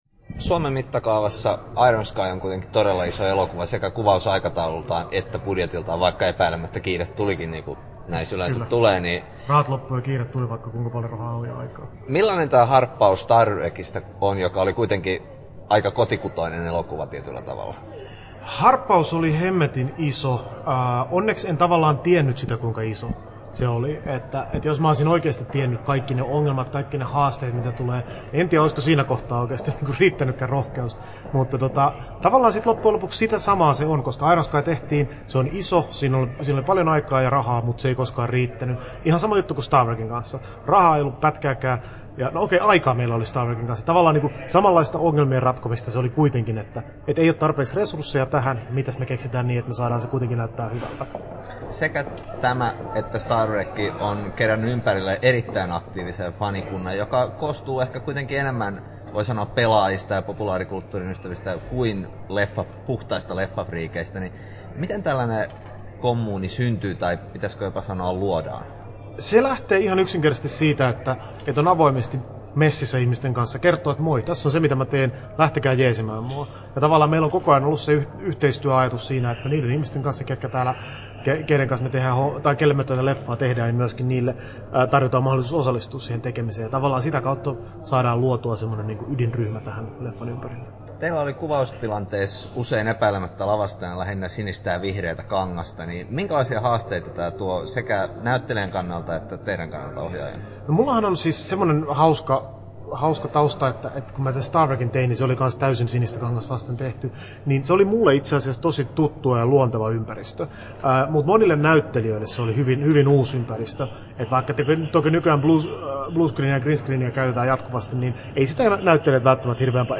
Timo Vuorensolan haastattelu Kesto